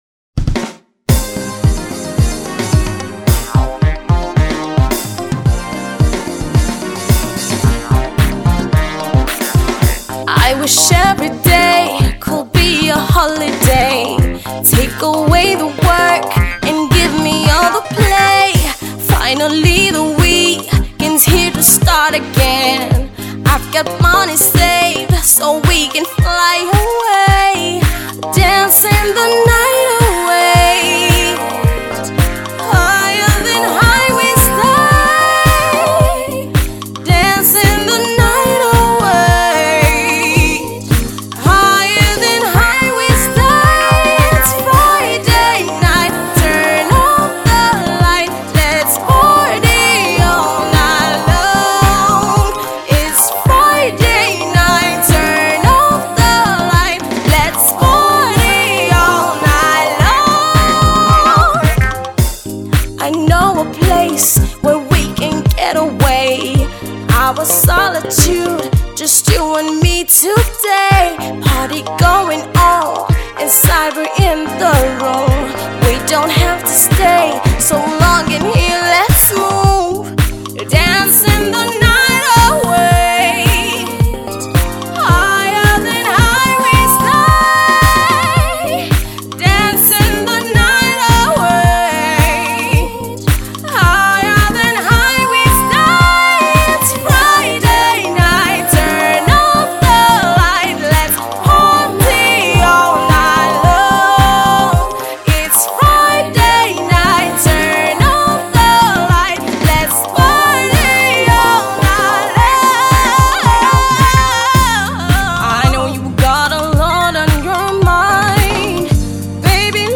reminds you of the ’70s/’80s era Dance music